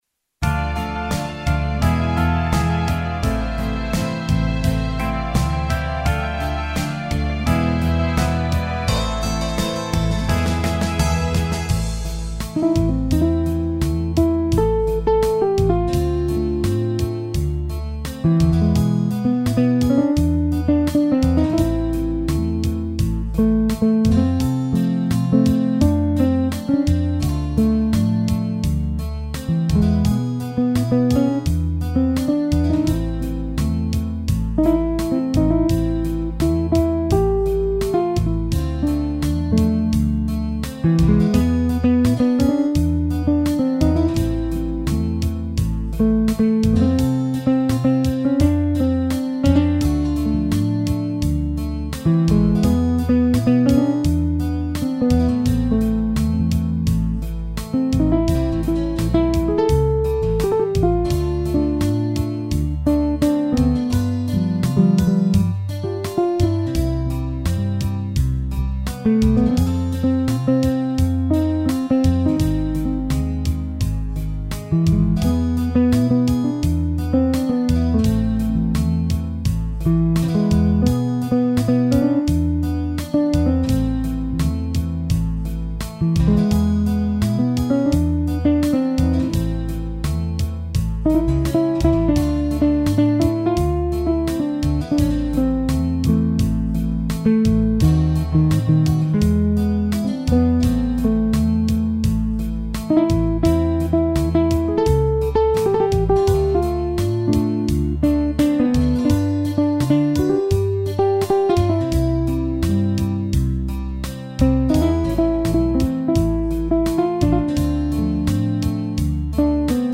teclado